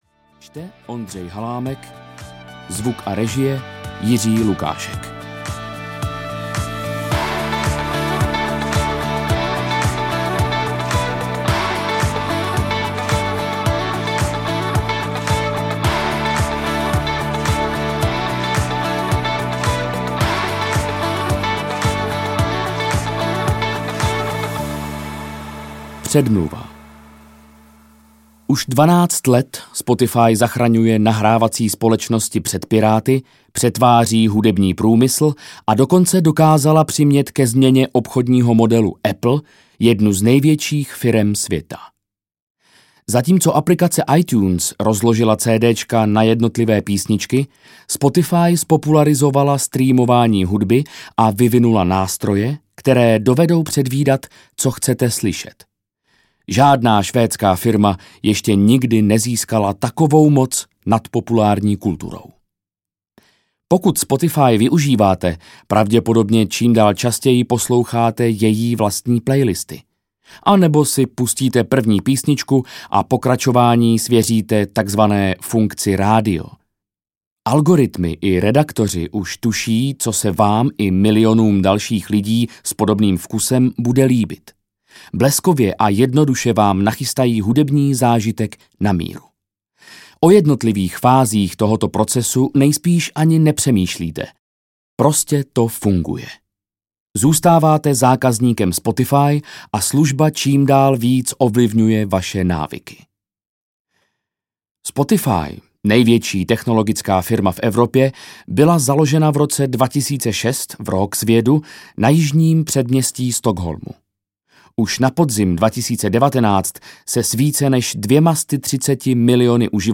Spotify audiokniha
Ukázka z knihy